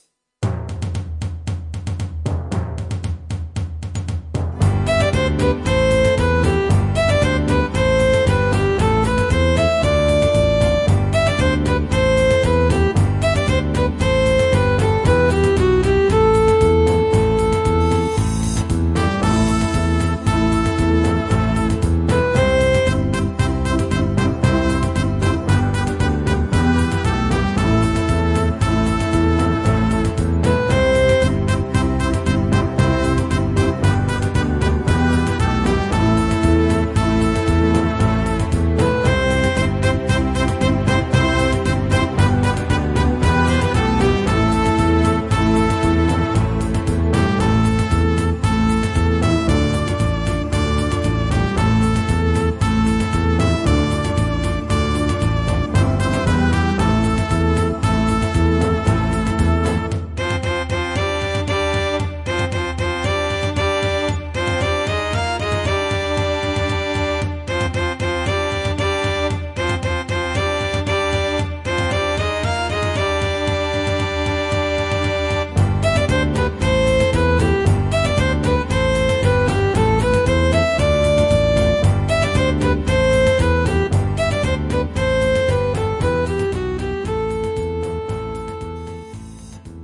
MIDI backing track